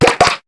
Cri de Crabagarre dans Pokémon HOME.